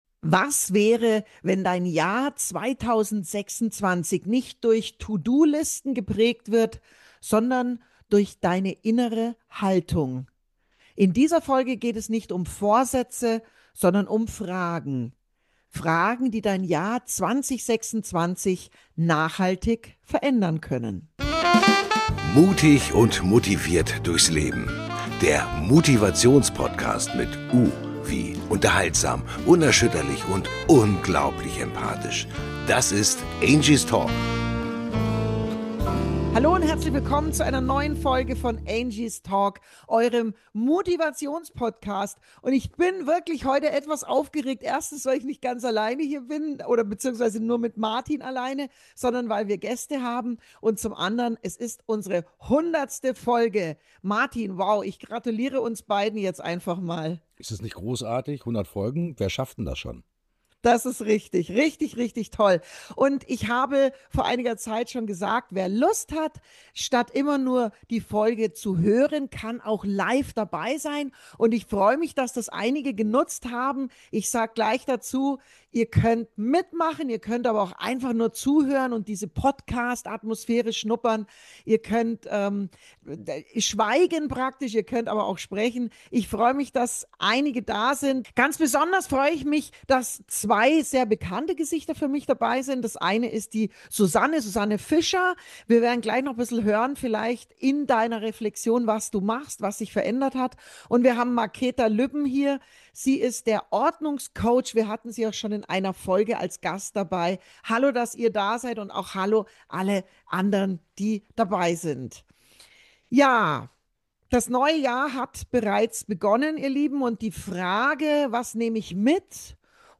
In dieser besonderen Podcastfolge habe ich gemeinsam mit einigen Zuhörer:innen innegehalten und reflektiert.
Danke an alle, die live dabei waren – und an Dich, wenn Du diese Folge jetzt hörst. 2026 darf leise beginnen.